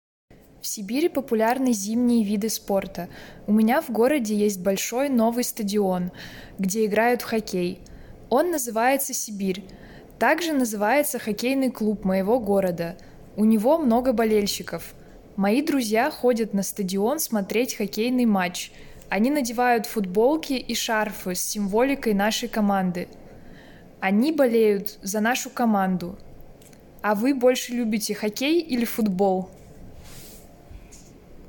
féminin, adulte